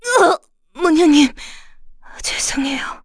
Laudia-Vox_Dead_kr.wav